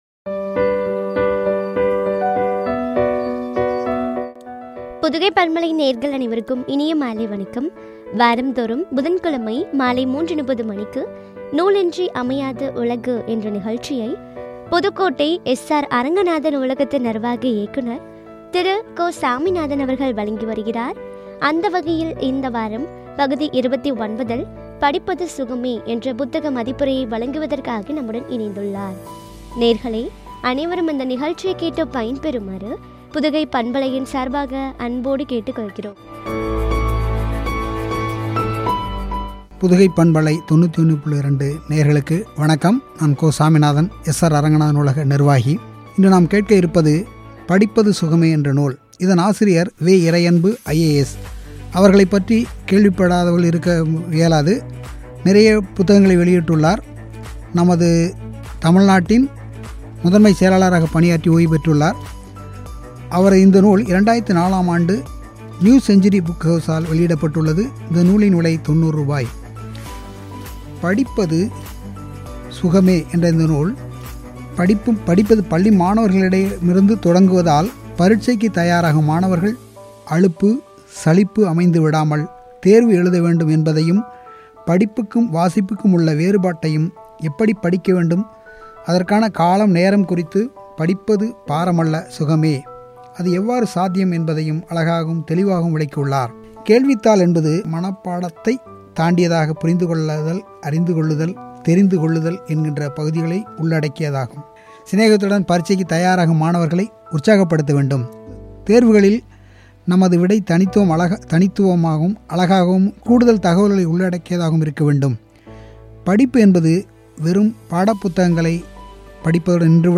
புத்தக மதிப்புரை (பகுதி -29) குறித்து வழங்கிய உரையாடல்.